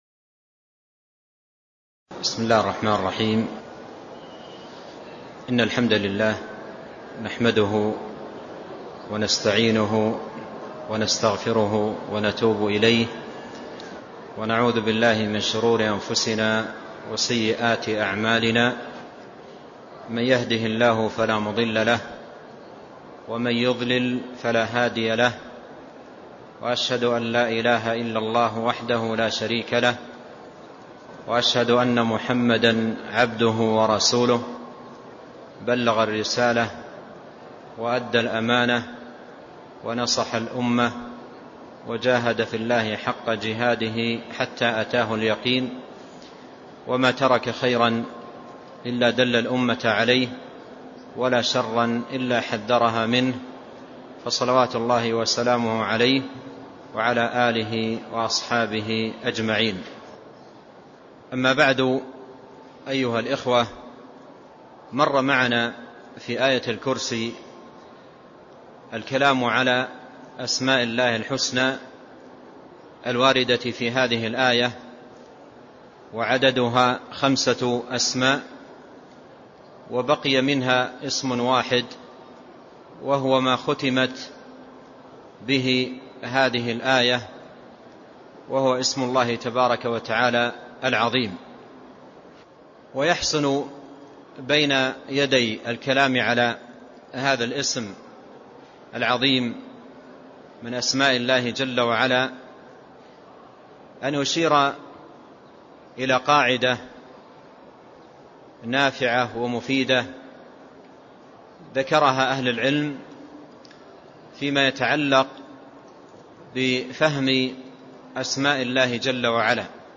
تاريخ النشر ١٢ جمادى الآخرة ١٤٢٧ هـ المكان: المسجد النبوي الشيخ